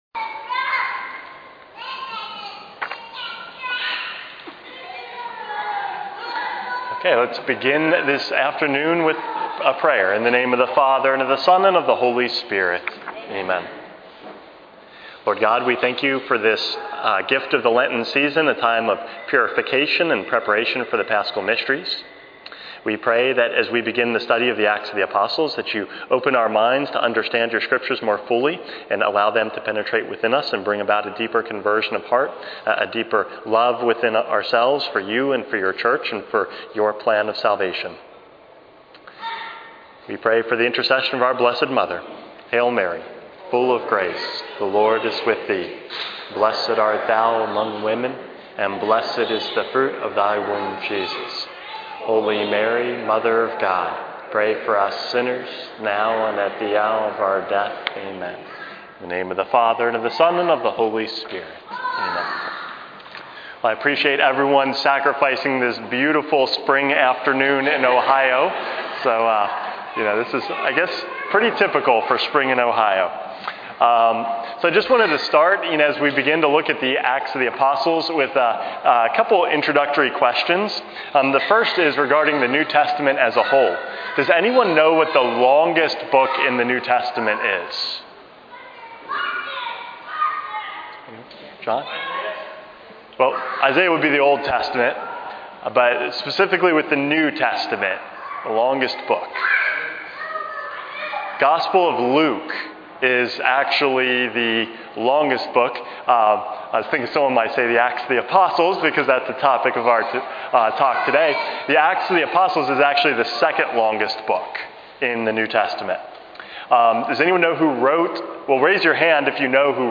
Lenten Talks 2025 - Part 1 of 2